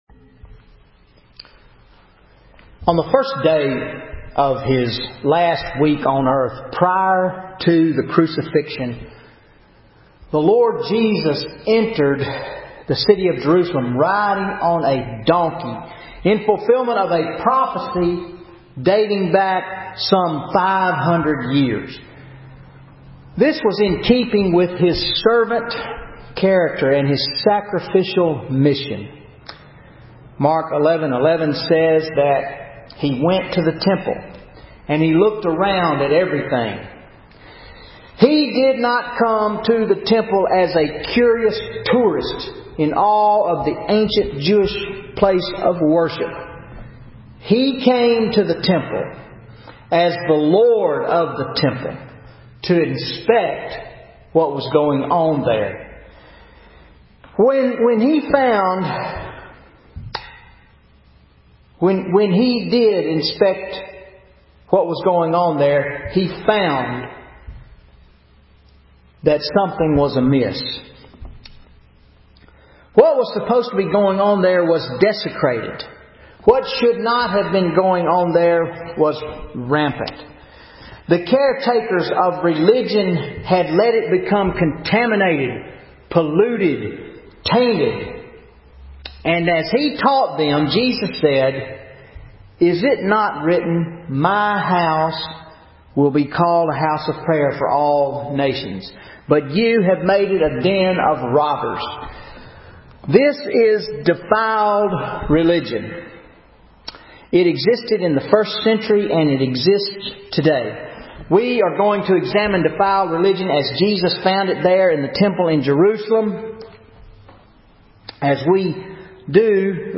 Sermon April 21, 2013 Mark 11:11-33 Defiled Religion – First Presbyterian Church